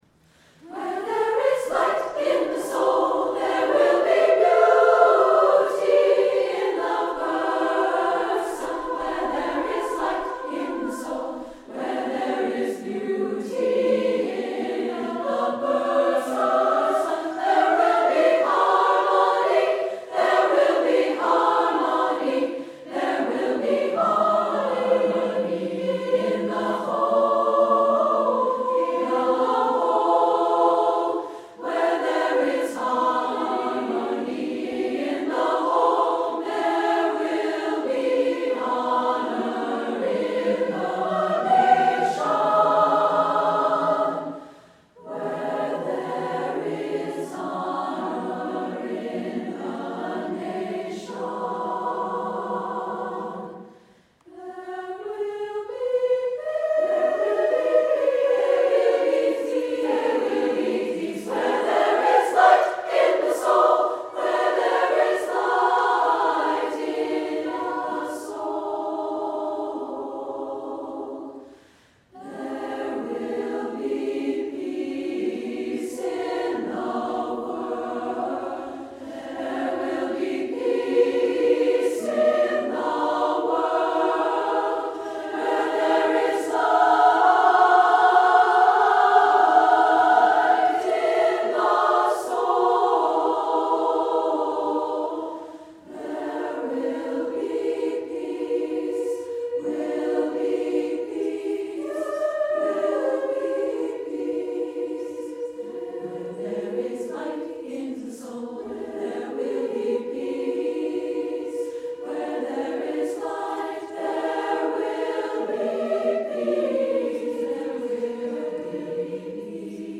SSA a cappella